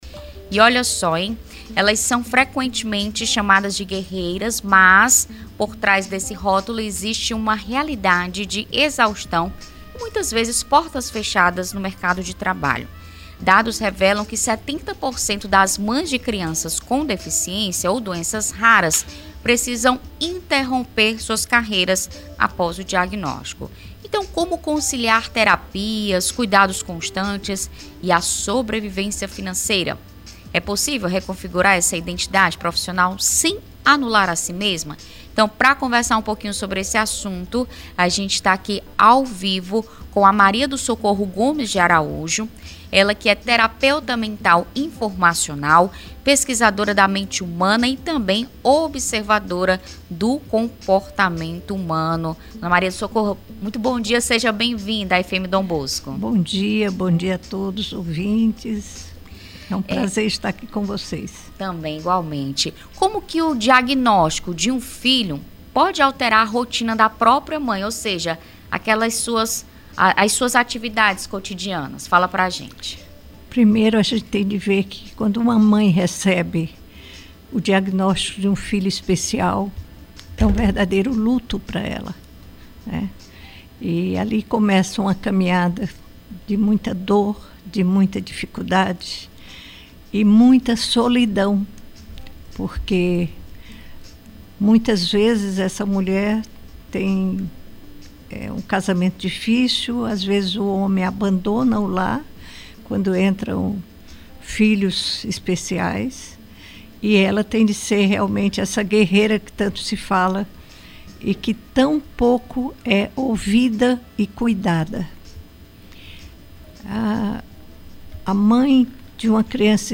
Mães atípicas: o desafio de cuidar dos filhos e da própria vida; confira entrevista